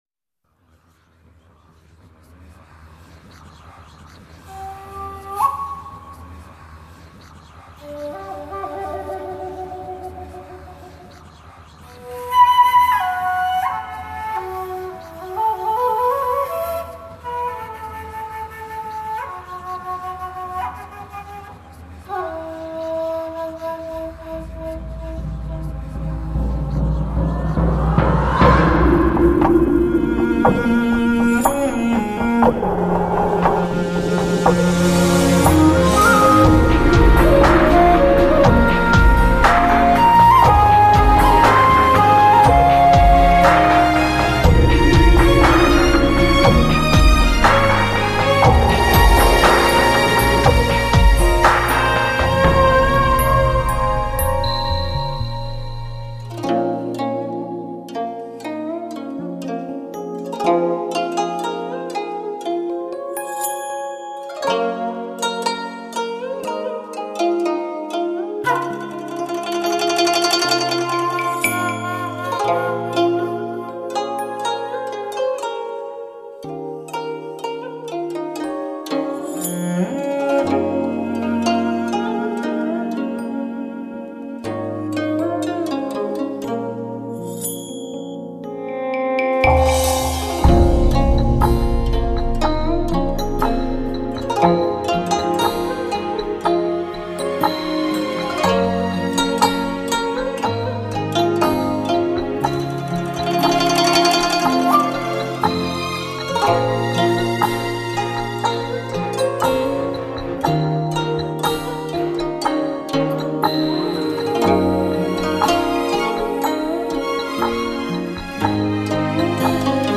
真正实现高清，全方位360环绕3D立体音效。